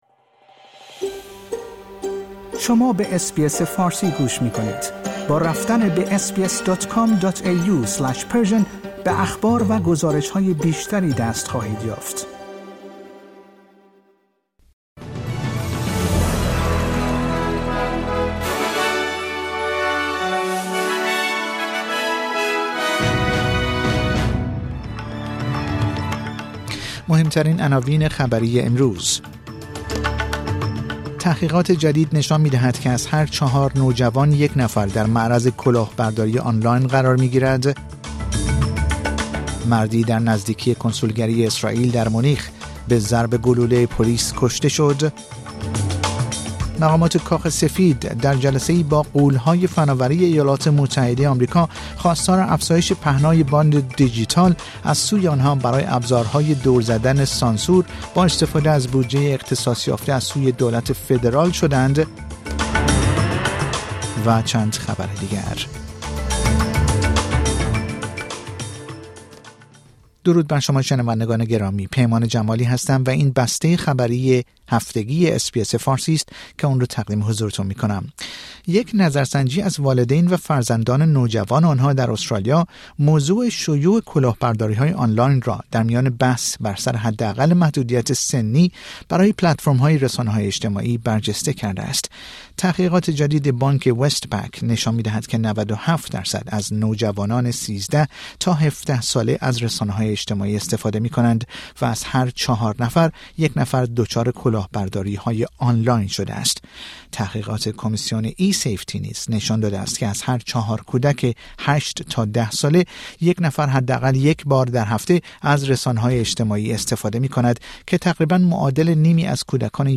در این پادکست خبری مهمترین اخبار استرالیا، جهان و ایران در یک هفته منتهی به شنبه هفتم سپتامبر ۲۰۲۴ ارائه شده است.